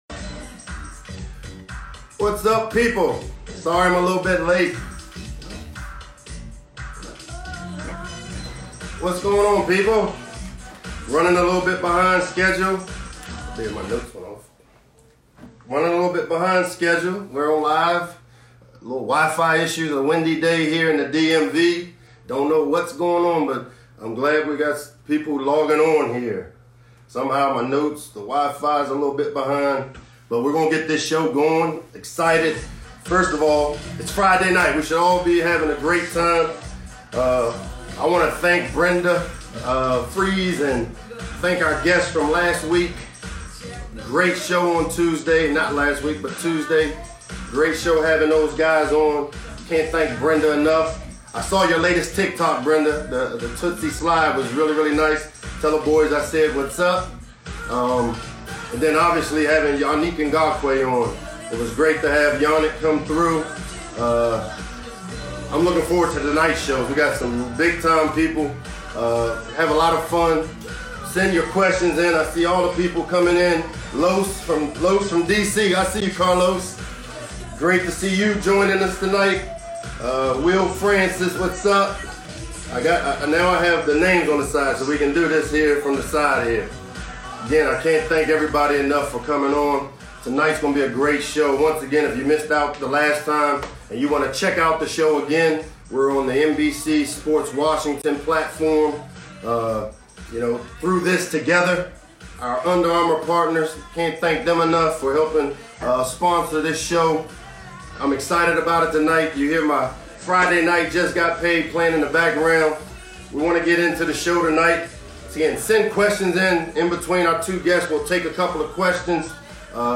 April 14, 2020 Late Night with Locks is an Instagram live show hosted by head football coach Michael Locksley every Tuesday and Friday evening at 7 p.m. This show featured NFL QB prospect Tua Tagovailoa & Ryan Zimmerman of the Washington Nationals.